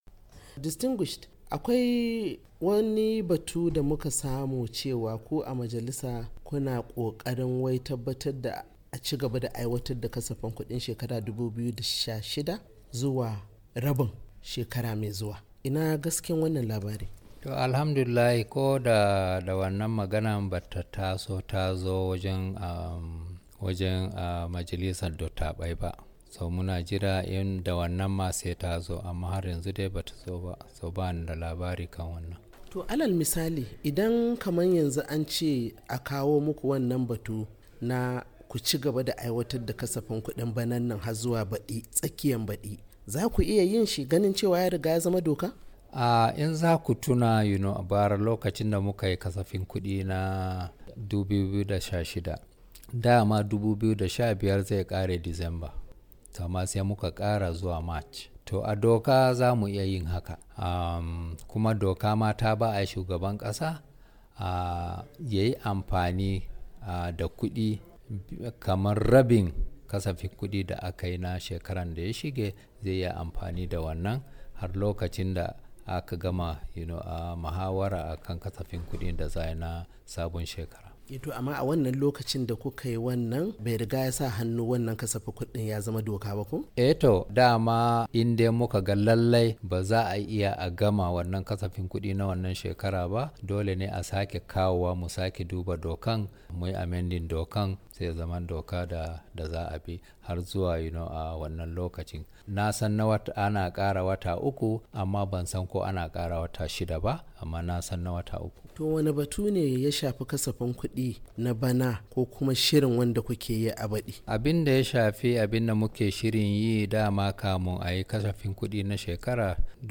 Sanata Yusuf Abubakar dan majalisar dattawan Najeriya a zantawar da yayi da Muryar Amurka ya tabbatar cewa a dokance suna iya tsawaita kasafin kudi na shekarar 2016 zuwa shekarar 2017